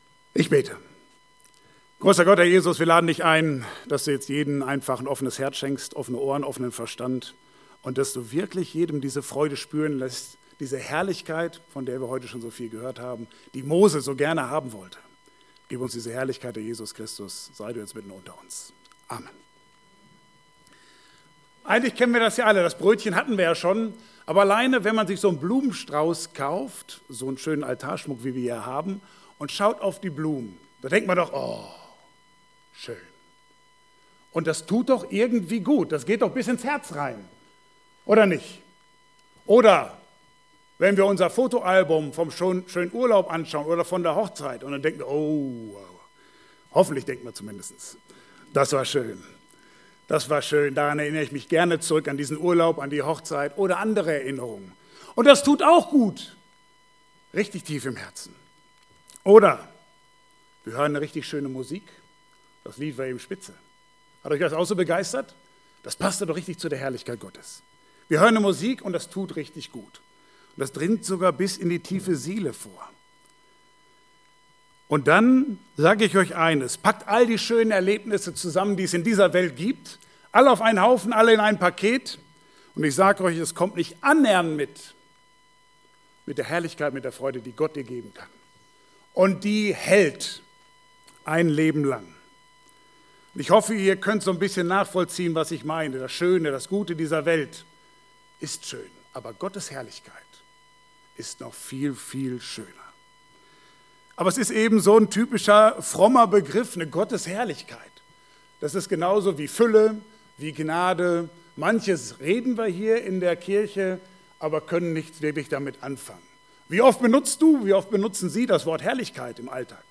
Passage: 2. Mose 33, 18-23 Dienstart: Gottesdienst « Wer ist Jesus?